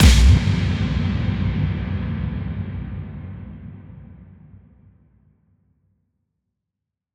Big Drum Hit 29.wav